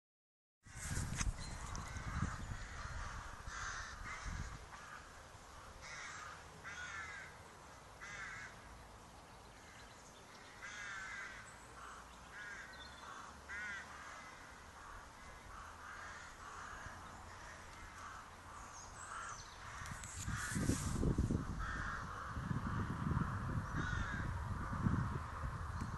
Crows
The distinctive call of crows in the tall trees surrounding Nunnington Hall